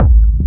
• Big Fat Techno Kick Span Slam Style.wav
Big_Fat_Techno_Kick_Span_Slam_Style_4af.wav